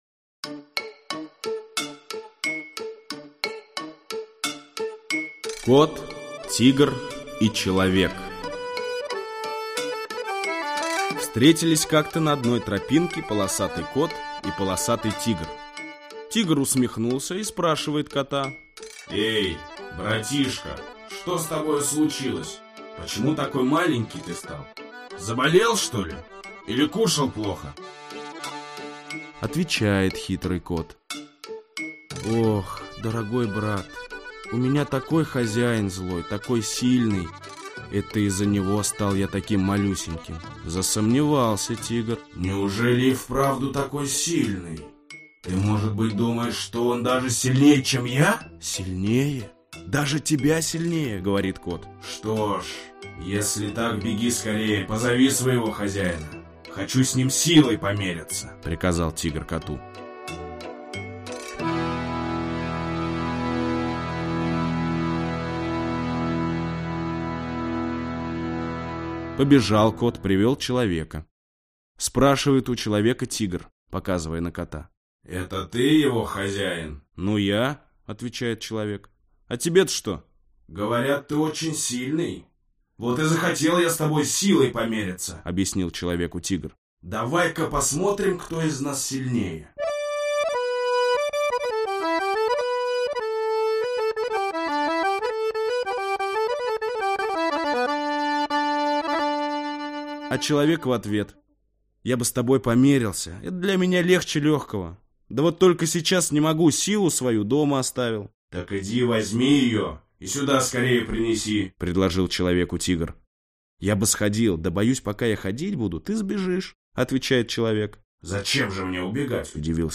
Аудиокнига Белый змей. Татарские народные сказки | Библиотека аудиокниг
Aудиокнига Белый змей. Татарские народные сказки Автор Группа авторов Читает аудиокнигу Чулпан Хаматова.